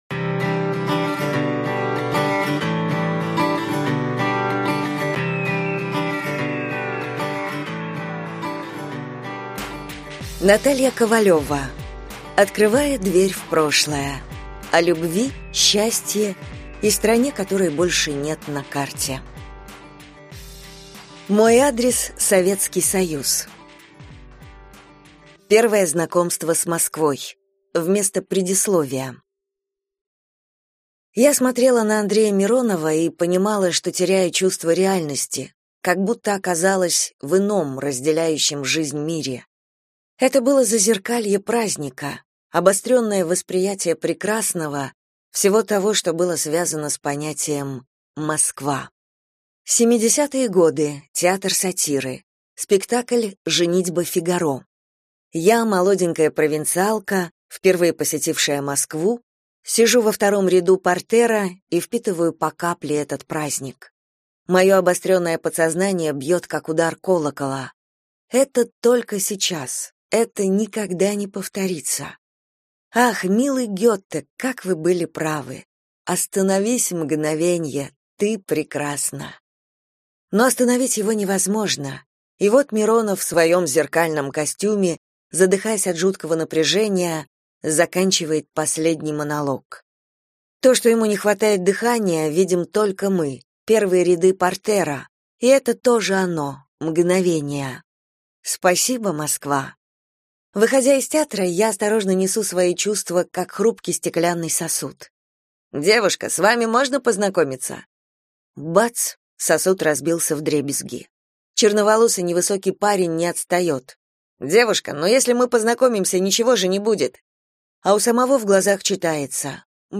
Аудиокнига Открывая дверь в прошлое. О любви, счастье и стране, которой больше нет на карте | Библиотека аудиокниг